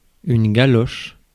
Ääntäminen
France: IPA: [ɡa.lɔʃ]